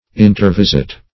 Intervisit \In`ter*vis"it\